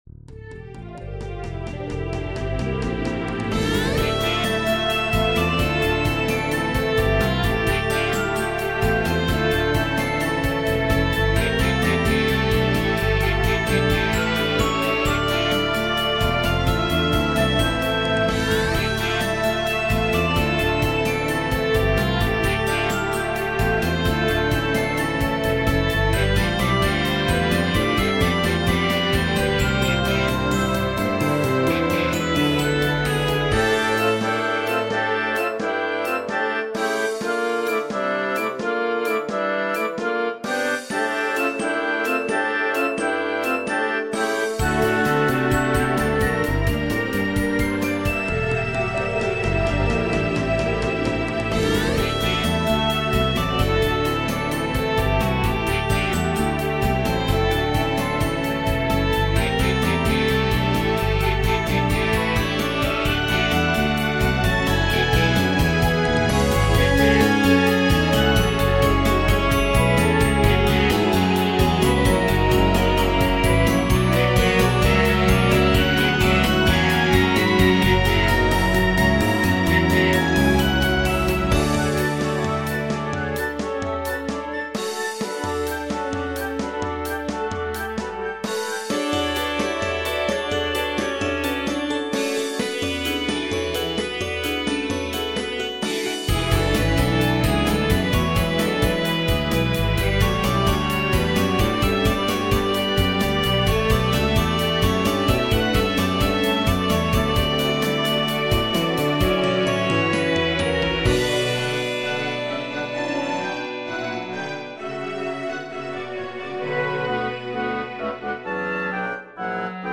Aeternum Proelium - Orchestral and Large Ensemble - Young Composers Music Forum
Although it probably does not sound intense enough to be battle music in an RPG, the melody when I first conceived it sounded to me like that kind of melody.